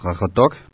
Summary Description Qaqortoq.ogg Greenlandic pronunciation of the Greenlandic town of Qaqortoq . Note: The accent is rather Danish.